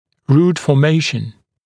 [ruːt fɔː’meɪʃn][ру:т фо:’мэйшн]формирование корня